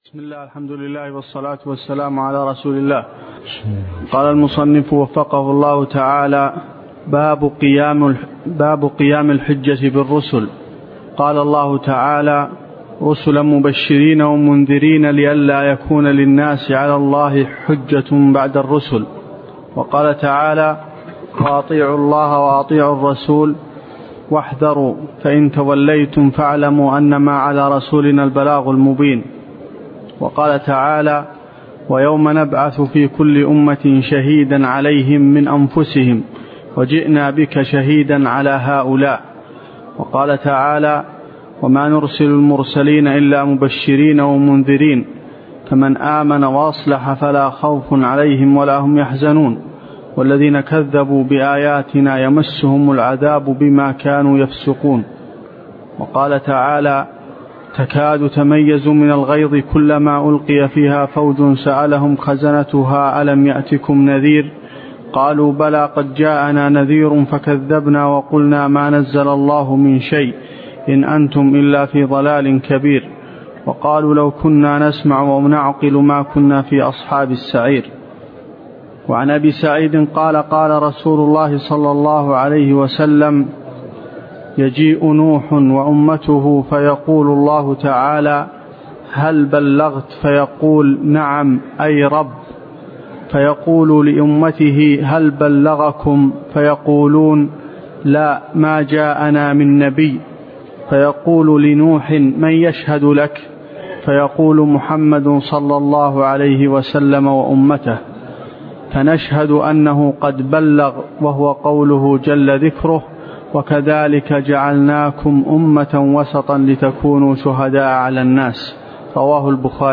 تفاصيل المادة عنوان المادة الدرس (9) شرح المنهج الصحيح تاريخ التحميل الأحد 15 يناير 2023 مـ حجم المادة 30.88 ميجا بايت عدد الزيارات 181 زيارة عدد مرات الحفظ 95 مرة إستماع المادة حفظ المادة اضف تعليقك أرسل لصديق